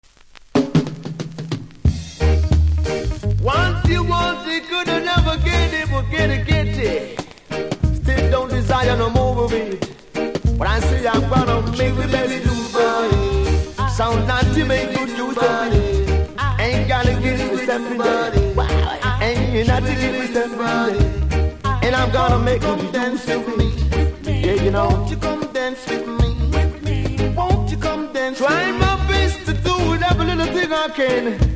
キズは多めですが音はそれほどでも無いので試聴で確認下さい。